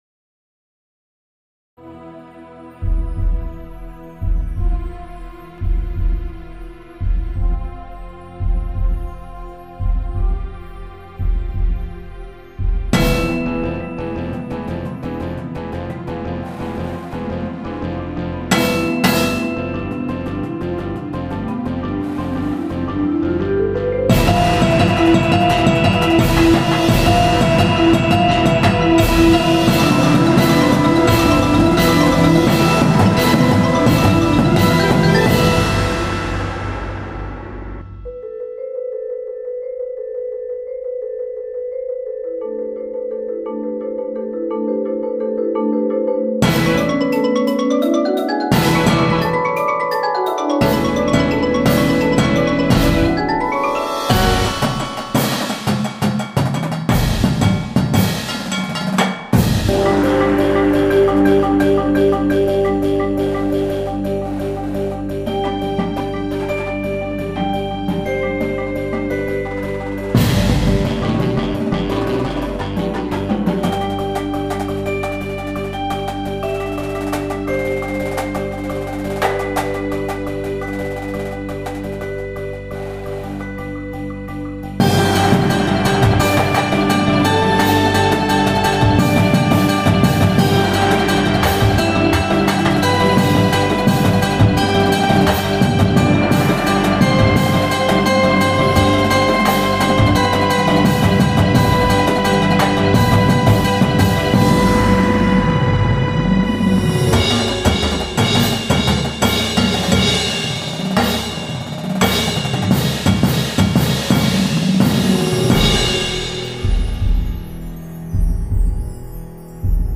Snares
Tenors (Sixes)
Bass Drums (5)
Cymbal Line
Glockenspiel
Xylophone 1
Marimba 1, 2, 3
Vibraphone 1, 2
Electric Guitar
Bass
Synth 1, 2, 3
Auxiliary Percussion 1, 2, 3